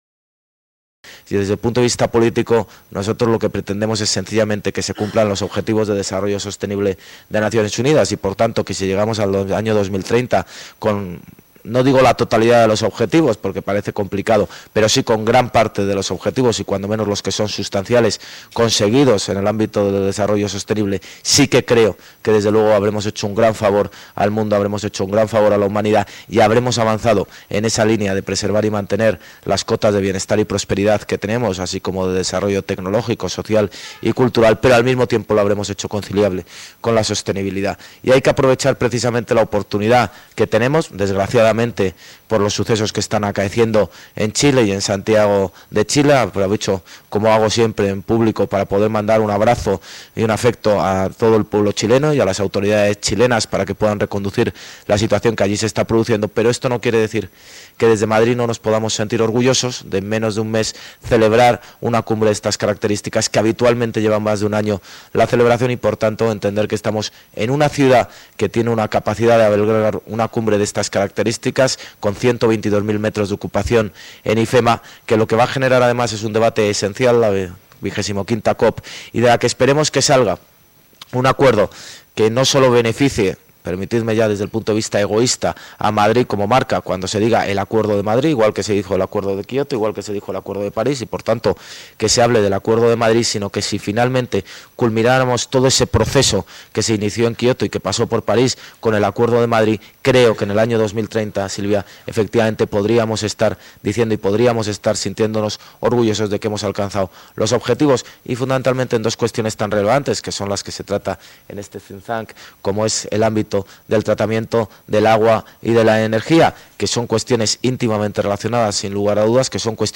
El alcalde de Madrid, José Luis Martínez-Almeida, se ha mostrado orgulloso de poder celebrar en Madrid la Cumbre del Clima en la apertura hoy jueves, 21 de noviembre, de las jornadas Madrid Aquaenergy Forum 19, un lugar de reflexión y debate sobre la energía y el agua y la transición energética.